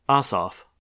Thomas Jonathan Ossoff (/ˈɒsɒf/
En-us-Ossoff.ogg.mp3